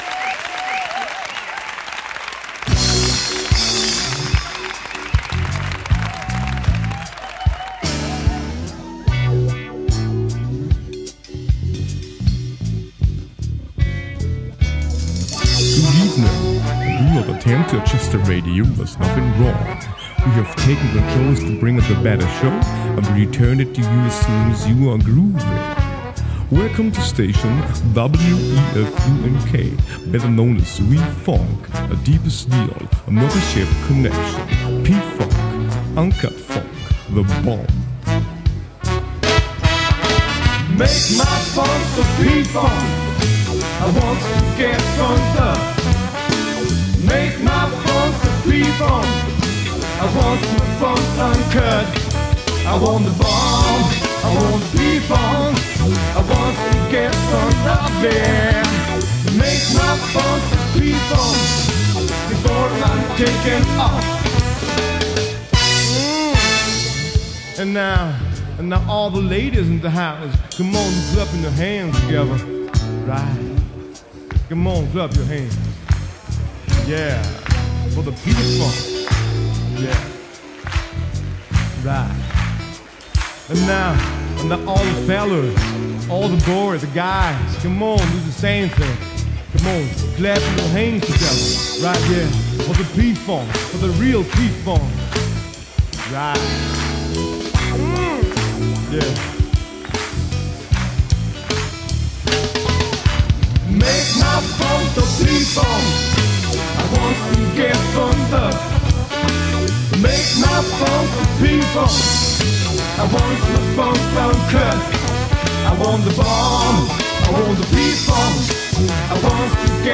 Jazz koncert
vokal
kitara
klaviature
bobni
saksofon
trombon